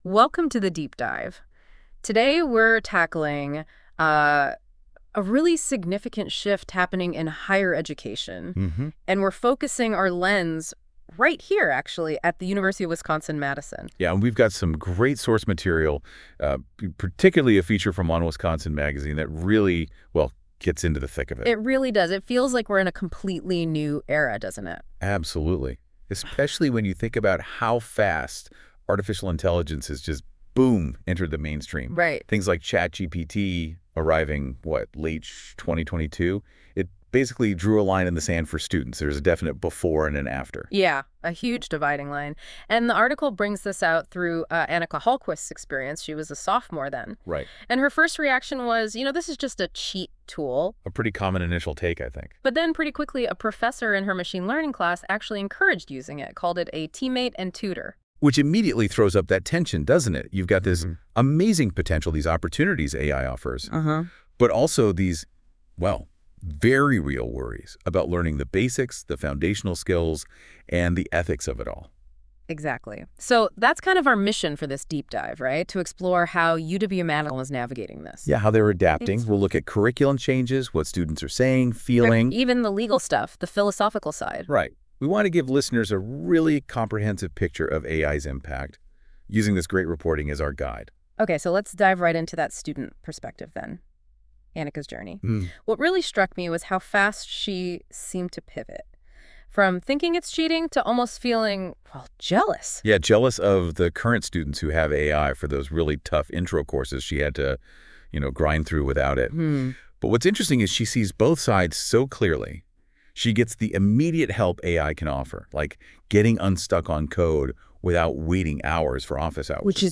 Click below to listen to the uncanny production.